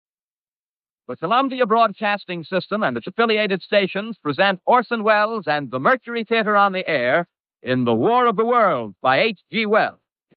Orson-Welles-War-Of-The-Worlds-BG-Cleanup.mp3